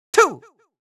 countIn2Far.wav